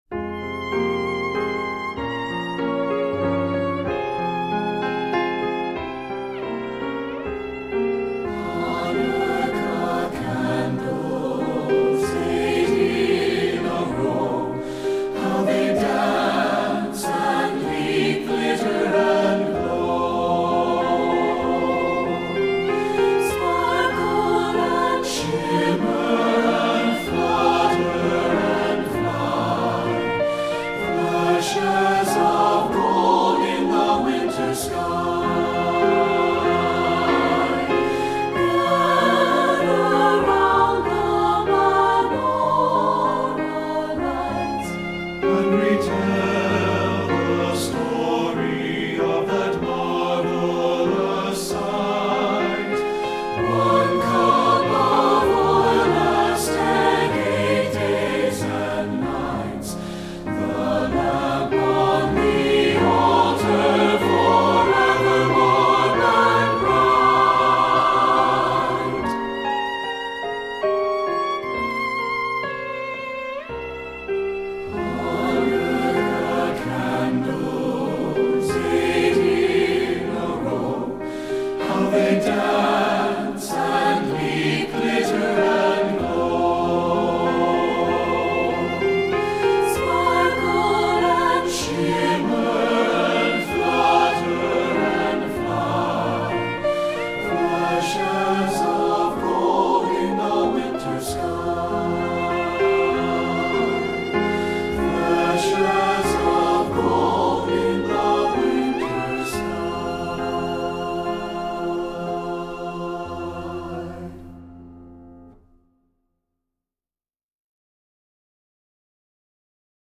Voicing: SATB and Violin